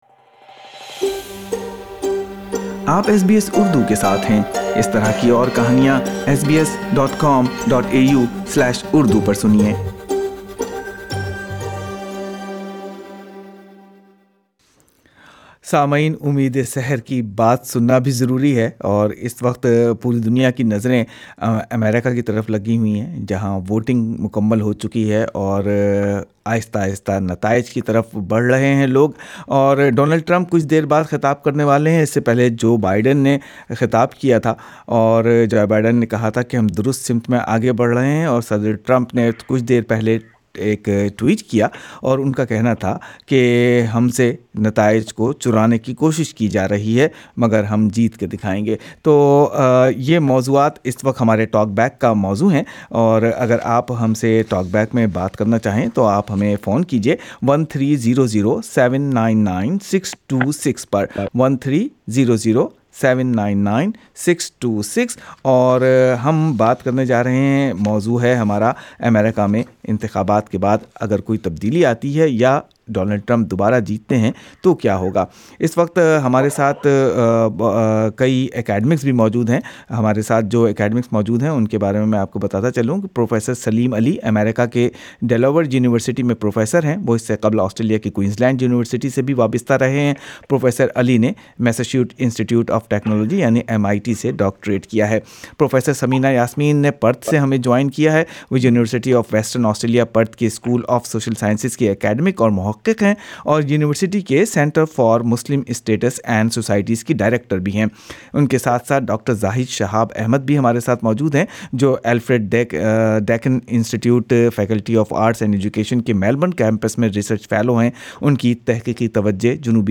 US Election - Panel discussion and Talkback Source: SBS URDU